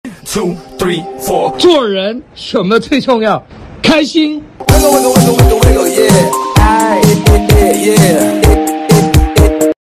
SFX做人什么最重要开心音效下载
SFX音效